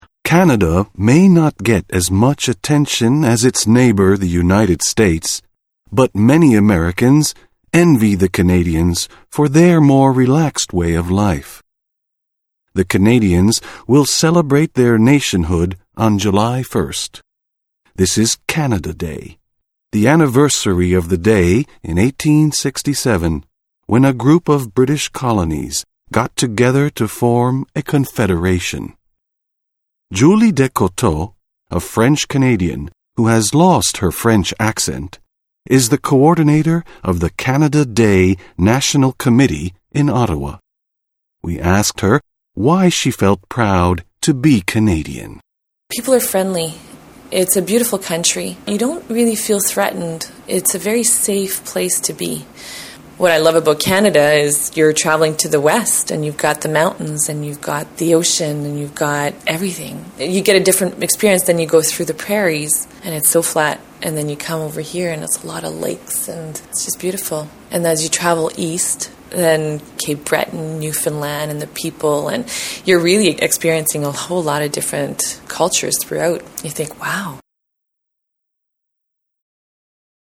INTERVIEW: a relaxed way of life